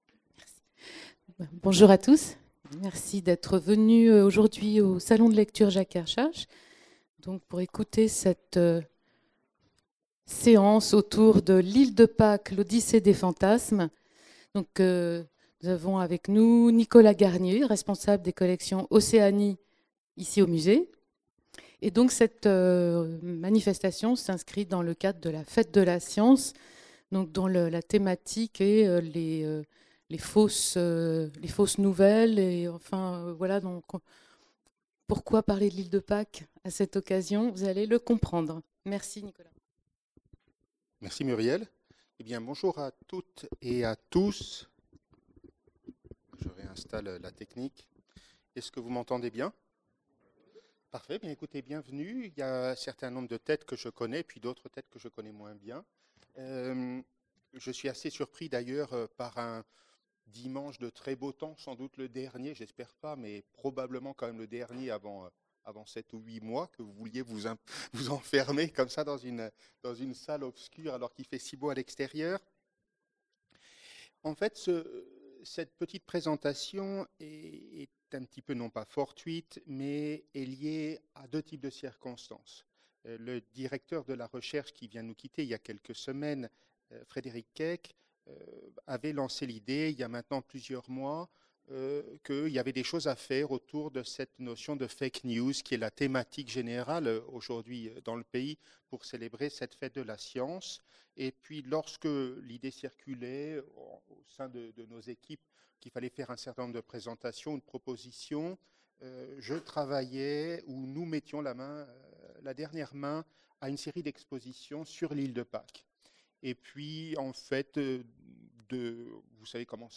conférence enregistrée au Salon de lecture Jacques Kerchache